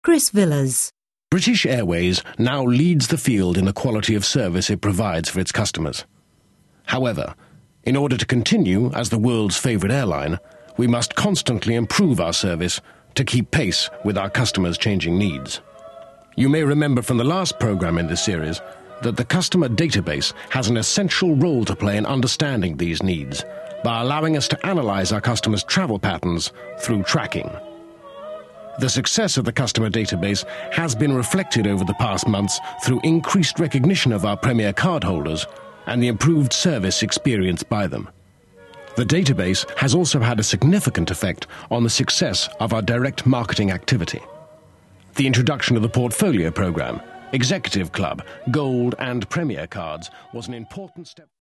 Description: RP: refined, knowledgeable, personable
Age range: 50s
Commercial 0:00 / 0:00
London, RP*